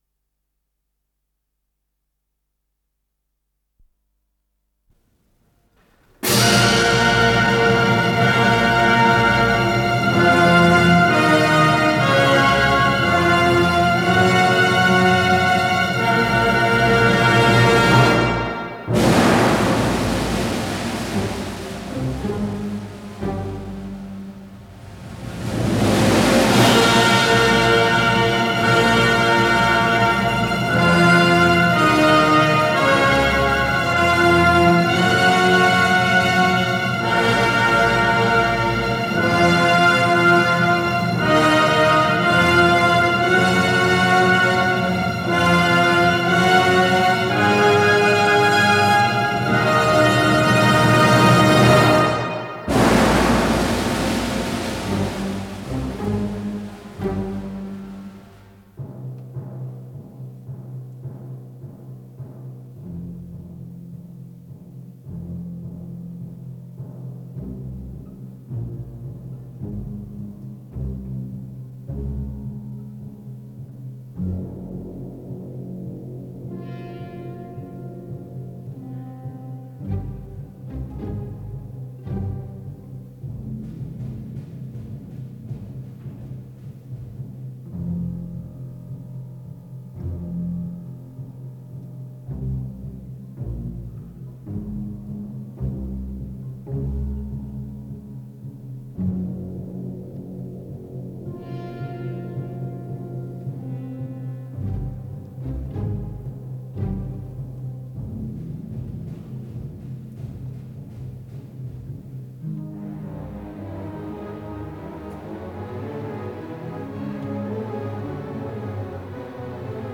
с профессиональной магнитной ленты
ВариантМоно